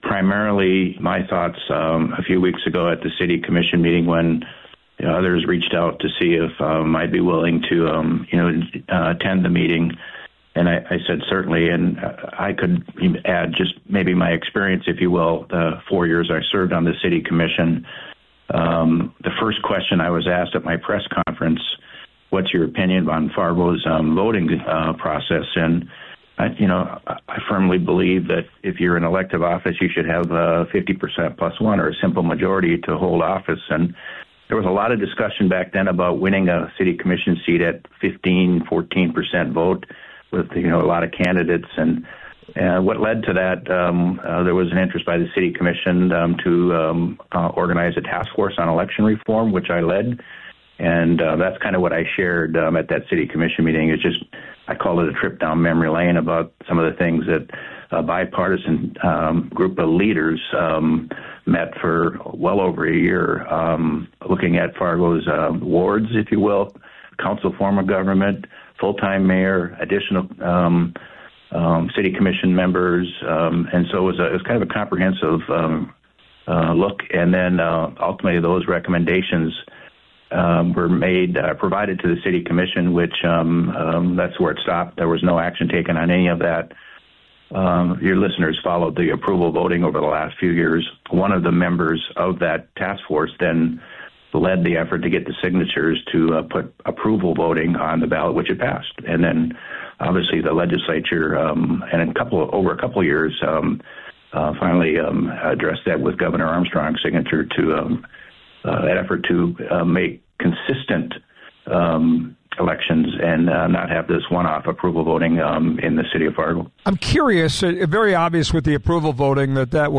Listen:  Tony Grindberg speaks on The Flag’s What’s On Your Mind?
tony-grindberg-midday.mp3